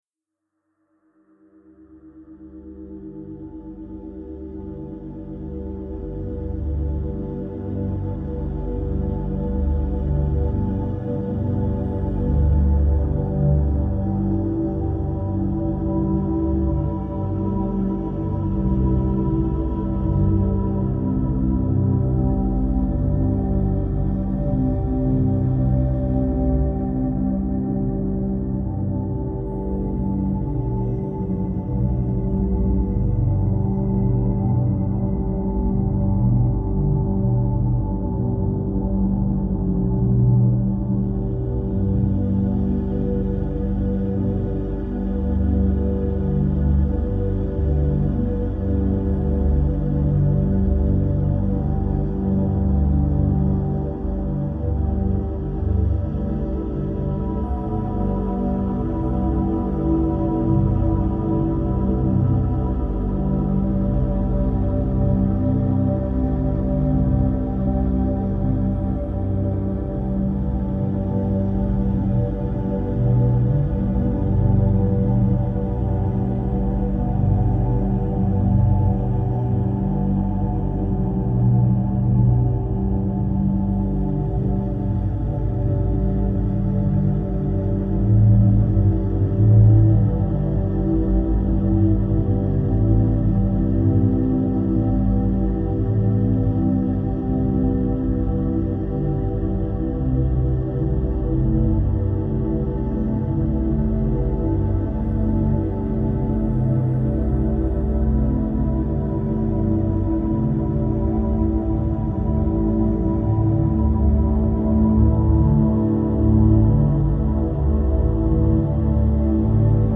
639 Hz